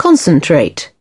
/əˈdɒpt/